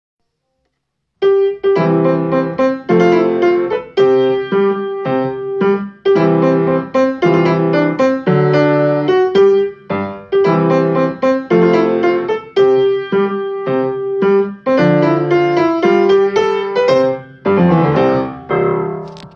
Easy Piano Solo